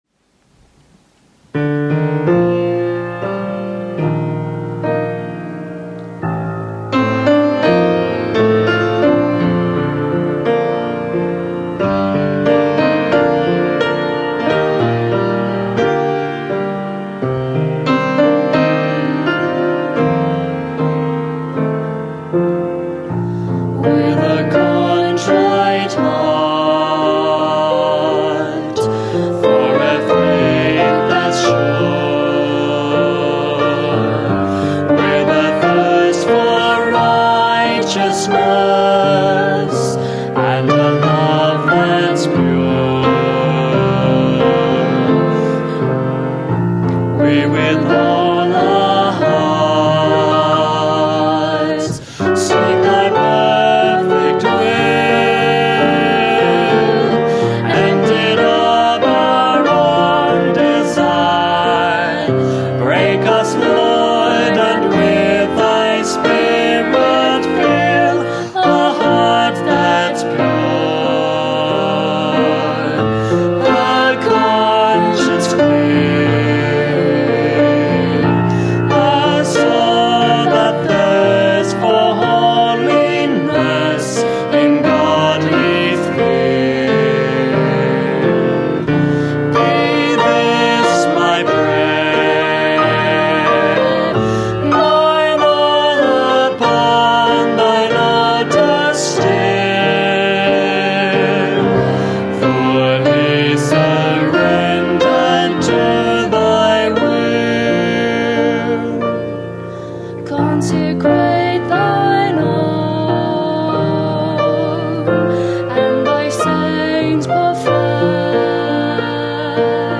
Bible Text: Nehemiah 3:1-11 | Preacher